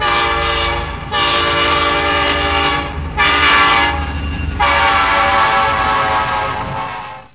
train_sound.wav